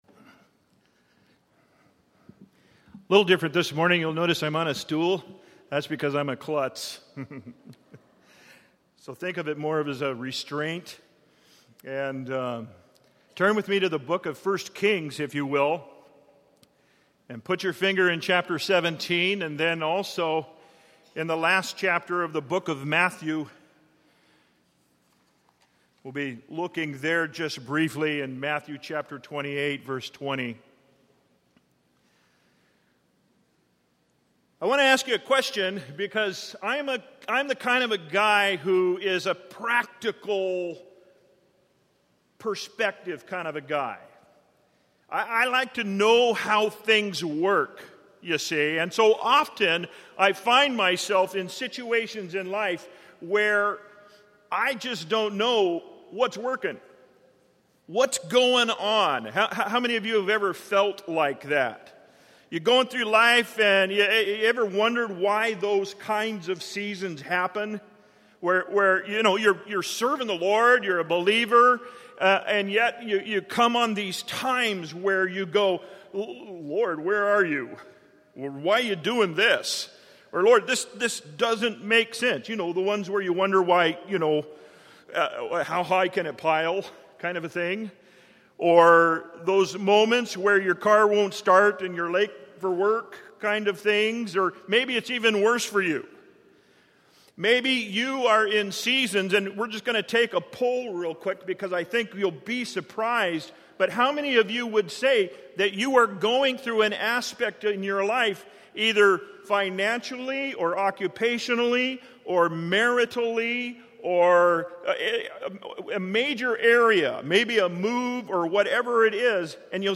A message from the series "(Untitled Series)." Scripture: Matthew 8:20